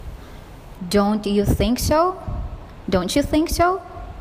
當單字的語尾爲「t」，而下一個單字的語首是「y」時，則會發成「tʃ」 的音。
Don’t⌒you 　         donchu